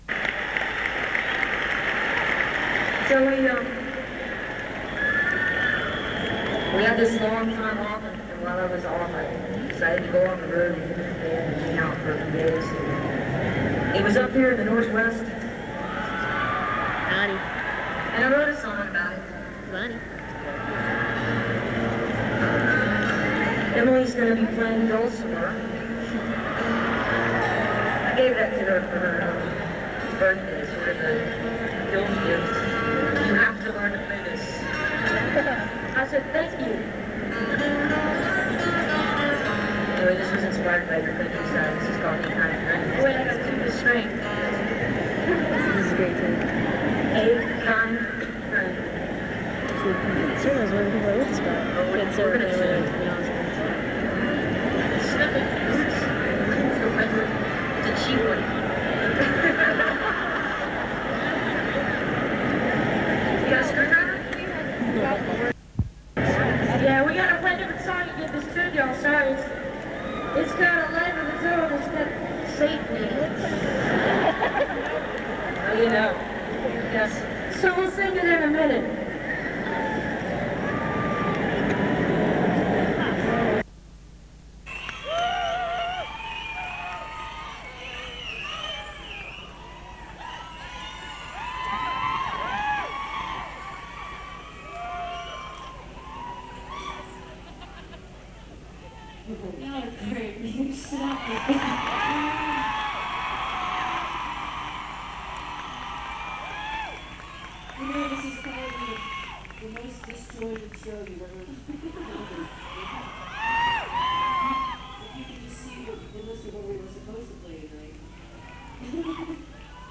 paolo solieri - santa fe, new mexico